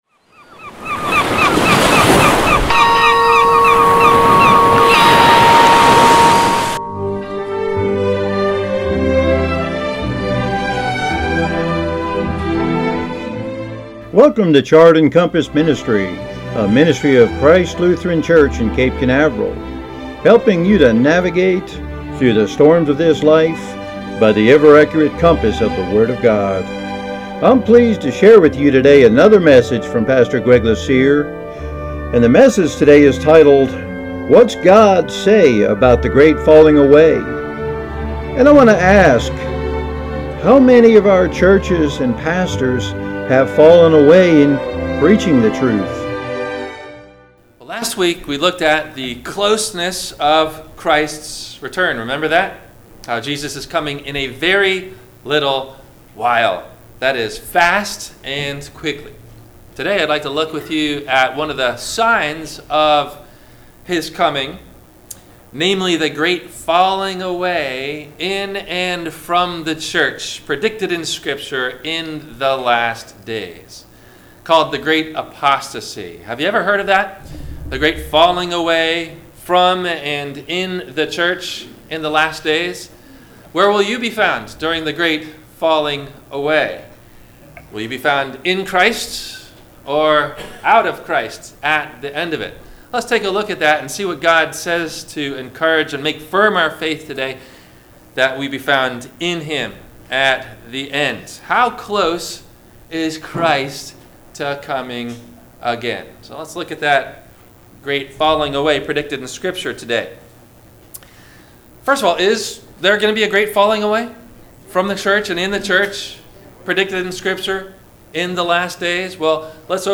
Questions asked before the Sermon message: